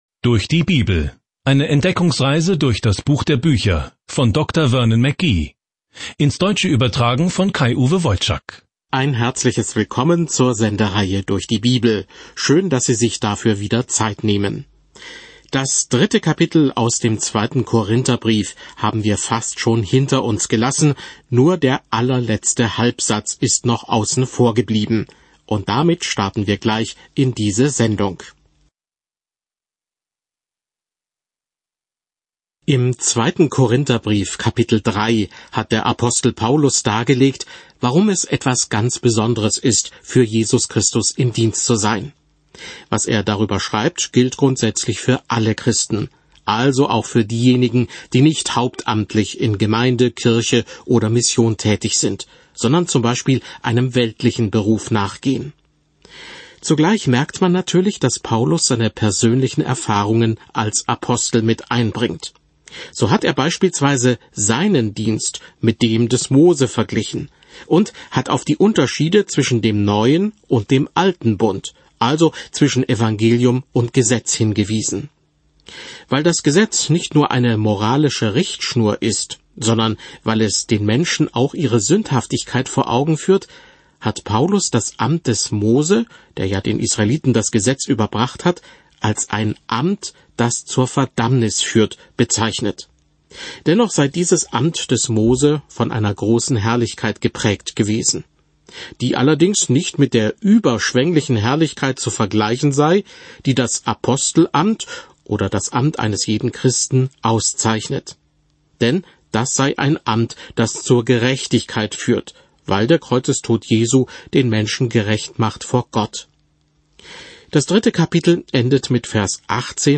Audiostudie